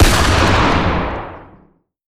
Sniper_Rifle2.ogg